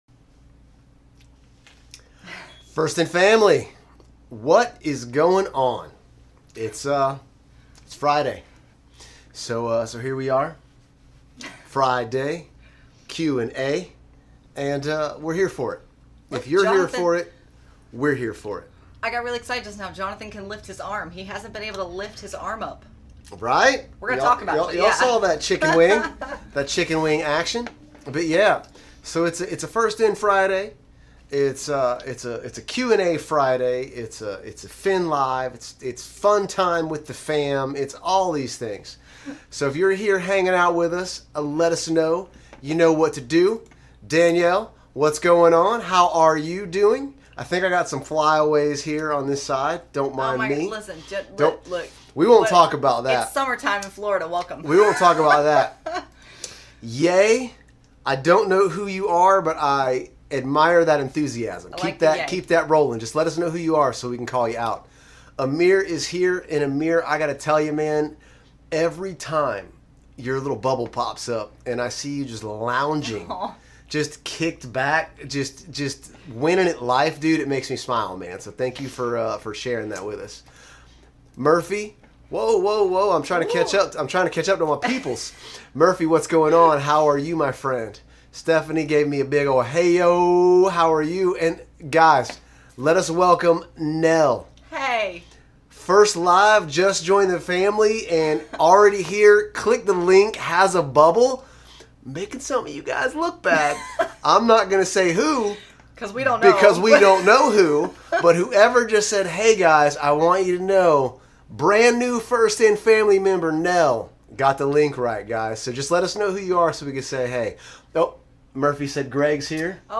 FIN LIVE Q+A: 4/9/21
We started the call with a FREE FOR ALL lol!